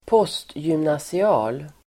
Uttal: [²p'ås:tjymnasia:l]